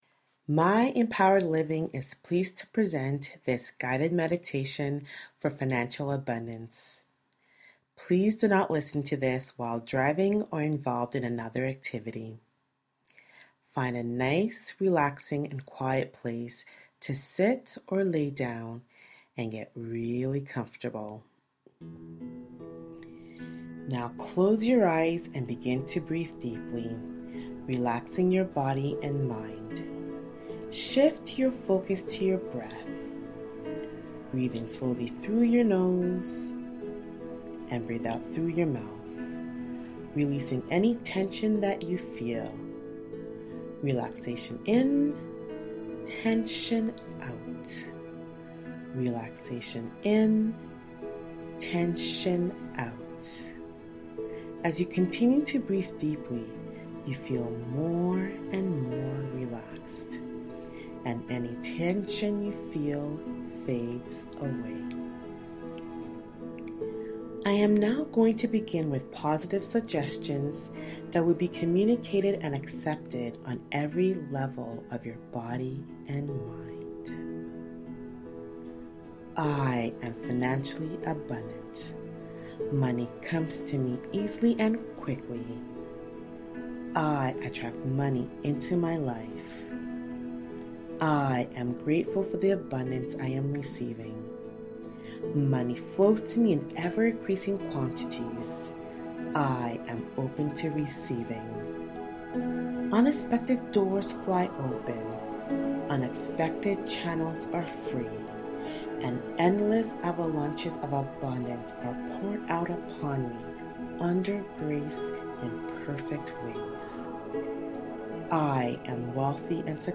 Money Manifestation Guided Visualization
MEL+Guided+Visualization+for+Financial+Abundance.mp3